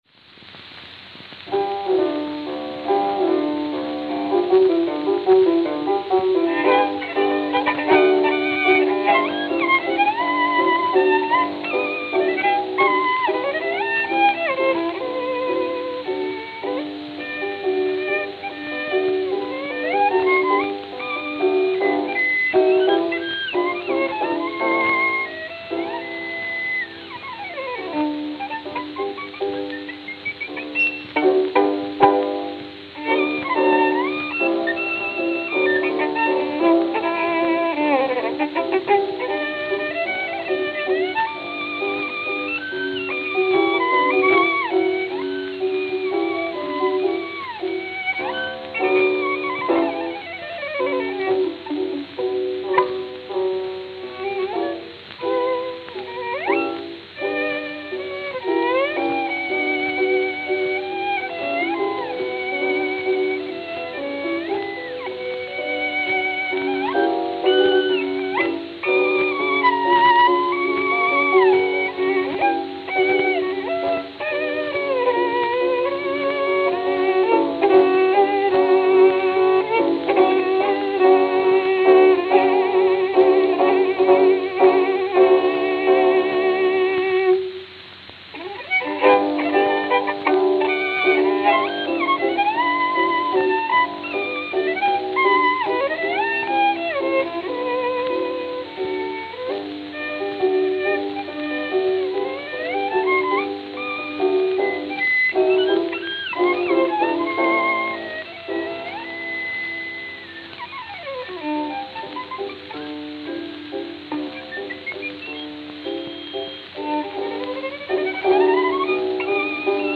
Camden, New Jersey